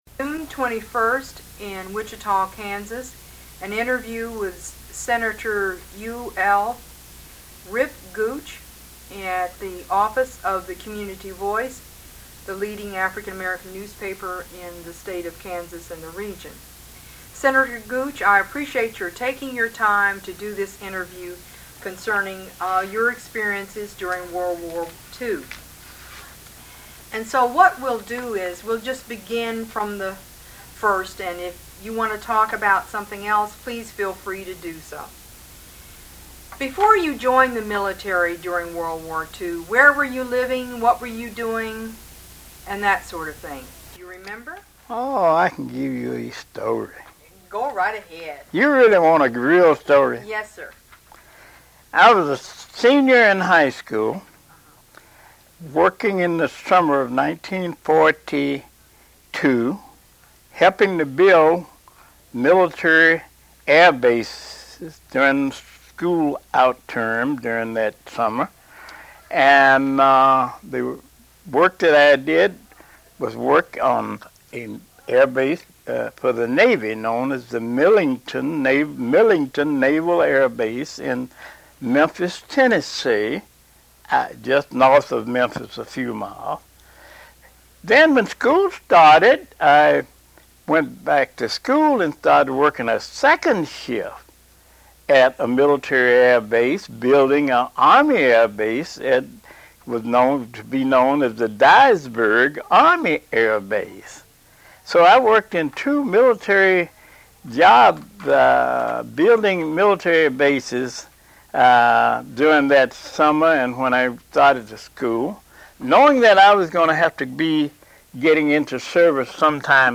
Interview with U.L. Gooch | KU Libraries Digital Collections
Oral history